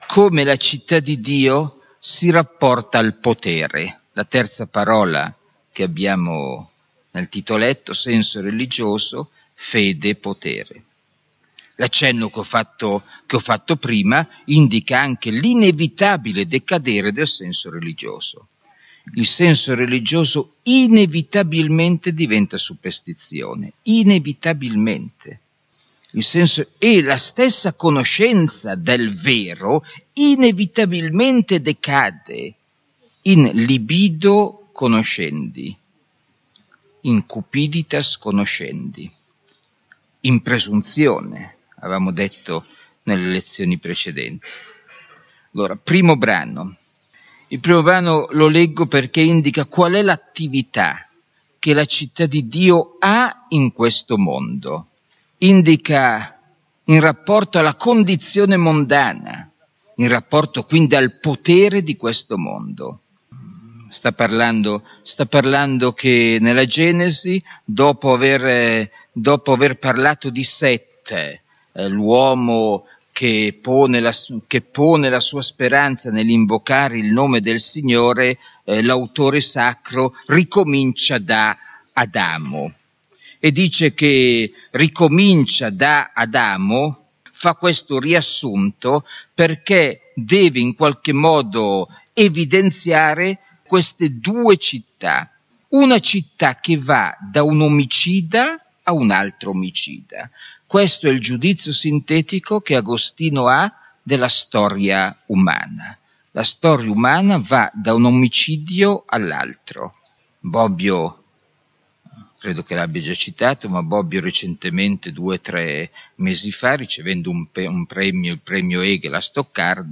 (Estratto da una lezione su S. Agostino)